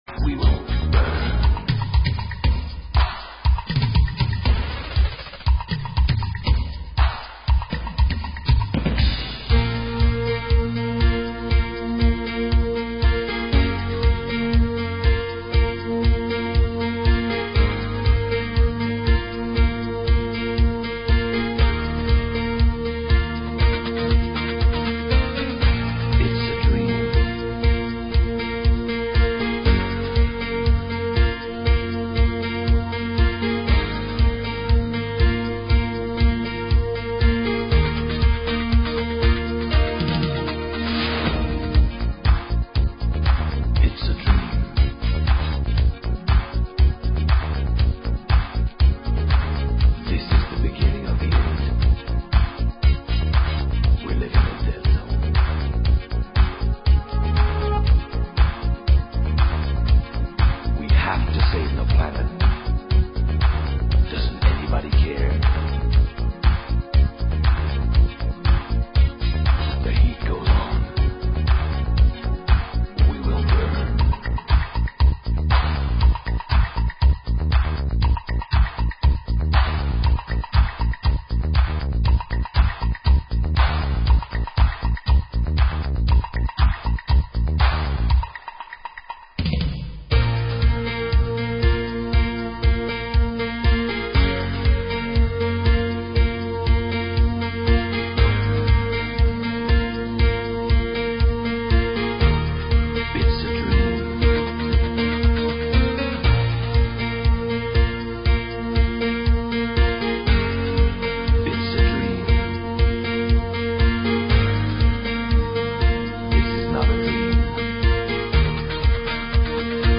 Genre: New Beat